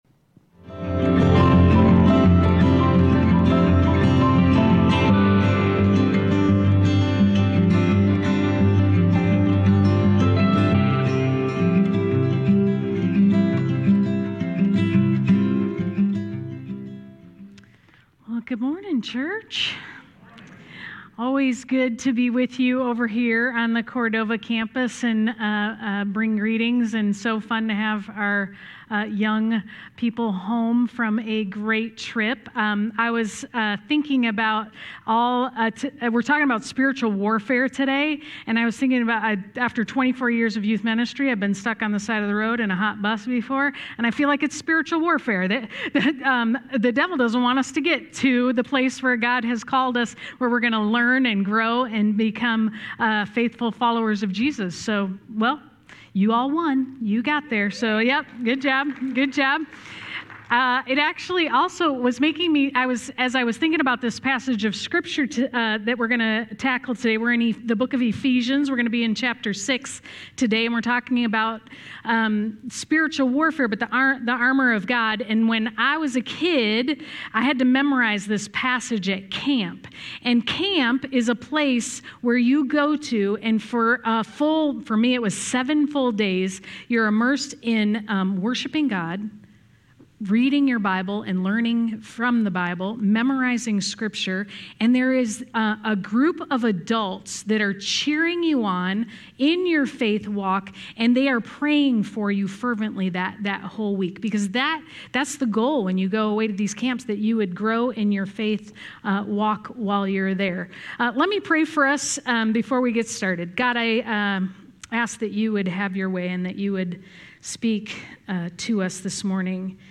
A message from the series "The Book of Ephesians."